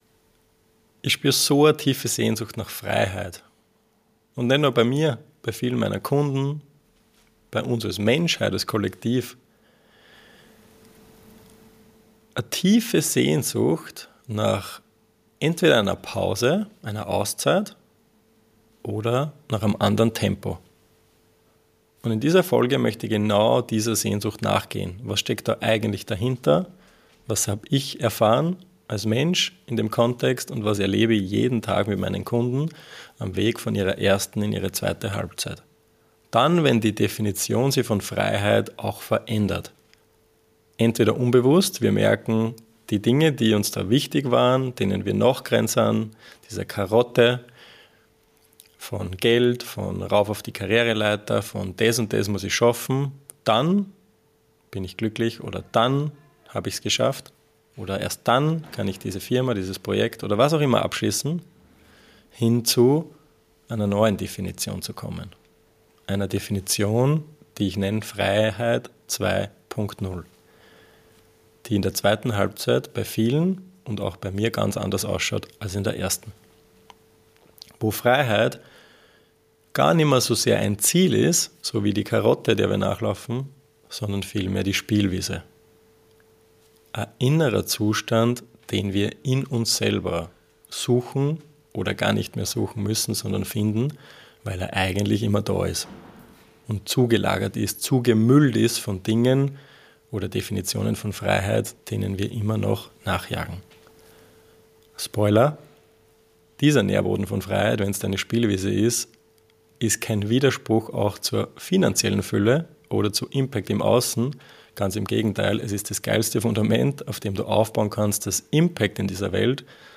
In meiner neuen Solo-Podcast-Folge gehe ich genau dieser Sehnsucht nach.